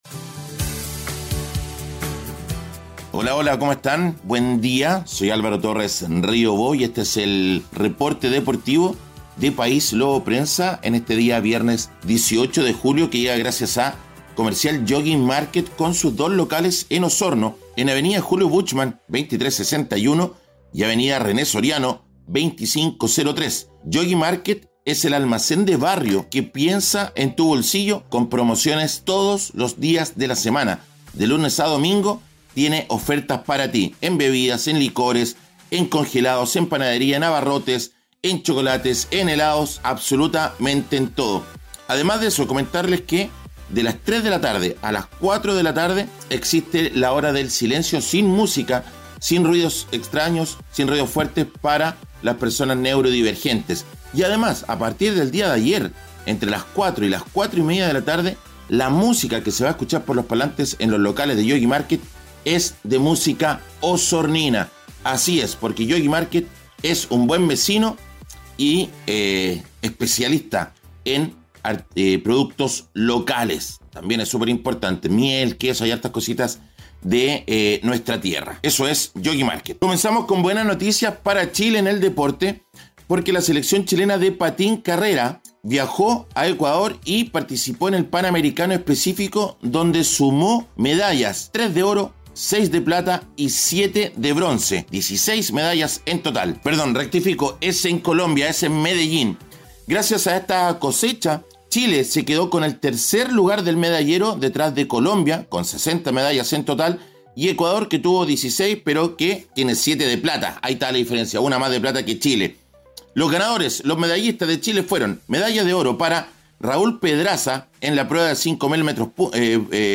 Conversamos con el basquetbolista sobre su experiencia. 🚗 Fórmula 1 en el GP de Bélgica: Todos los detalles de la carrera. 🛼 Chile Brilla en Sudamericano de Patín: La selección chilena logra el tercer lugar en el medallero en Colombia.